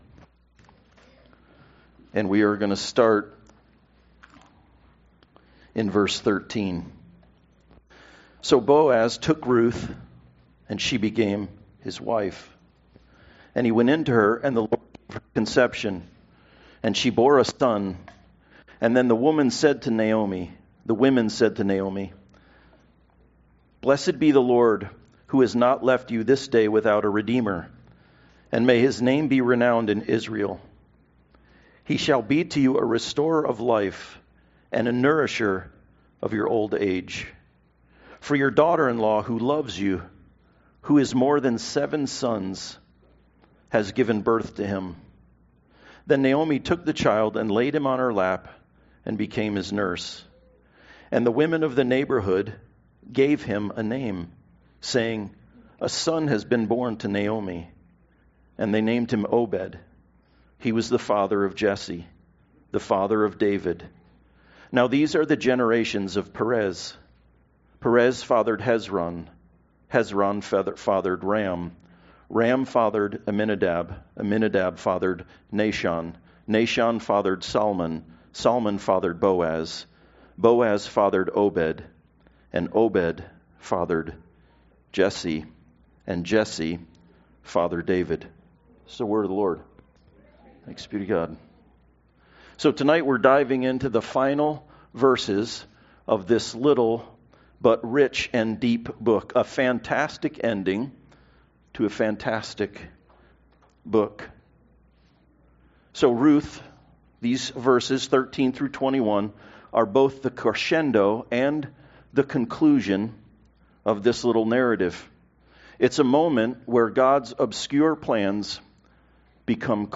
Ruth 4:13-20 Service Type: Sunday Service No matter what we face